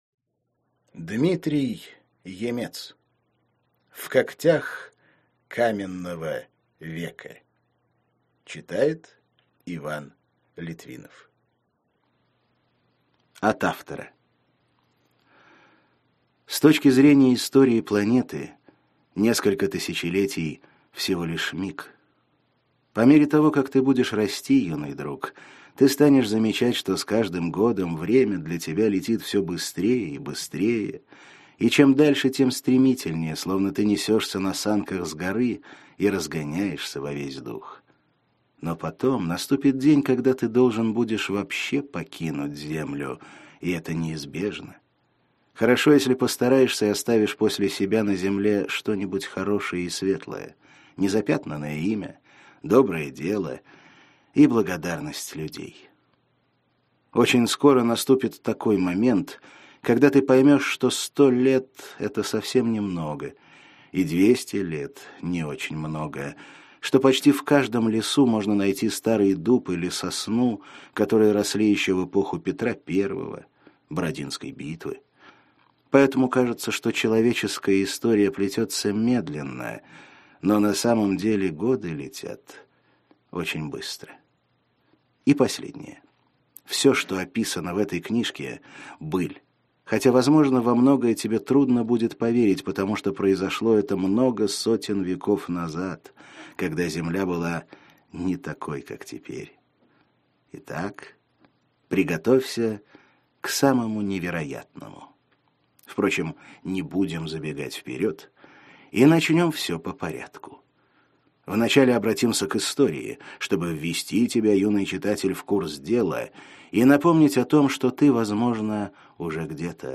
Аудиокнига В когтях каменного века | Библиотека аудиокниг
Прослушать и бесплатно скачать фрагмент аудиокниги